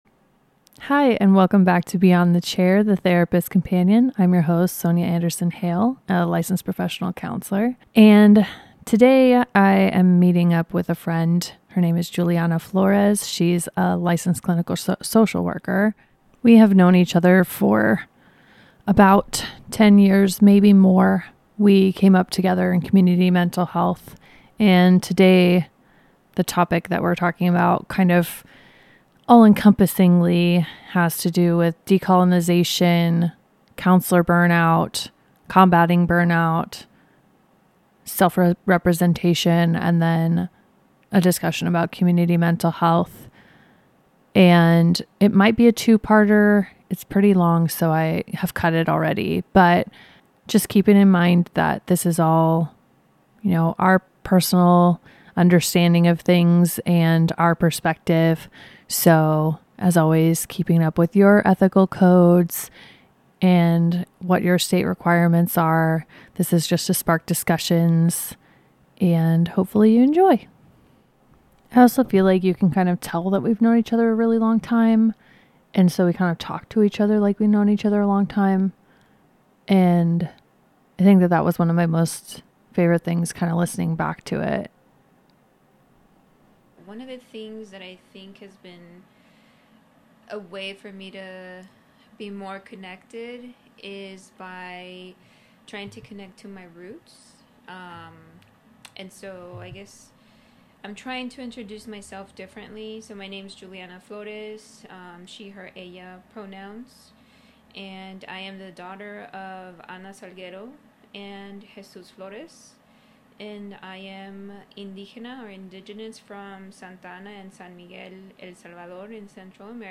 This conversation is about reclaiming identity, remembering our history, reconnecting with our roots, and finding sustainable ways to remain in this field without losing ourselves in it.